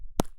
Added ball sfx
grass3.wav